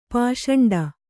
♪ pāṣaṇḍa